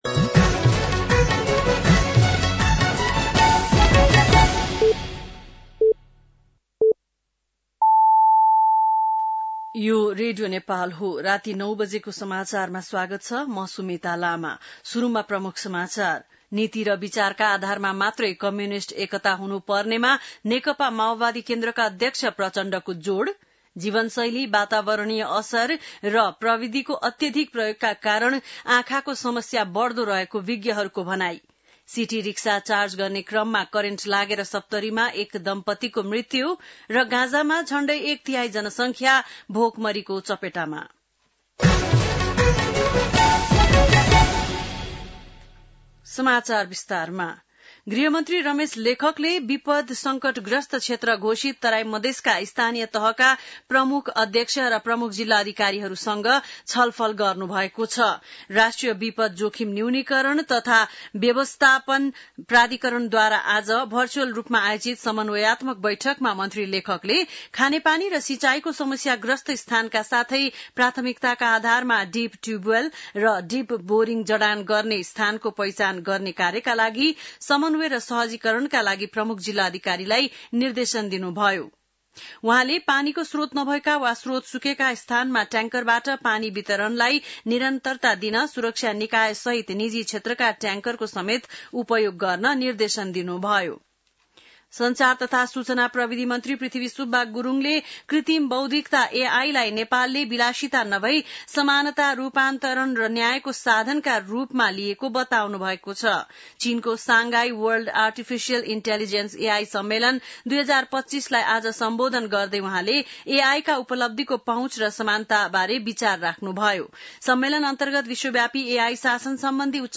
बेलुकी ९ बजेको नेपाली समाचार : १० साउन , २०८२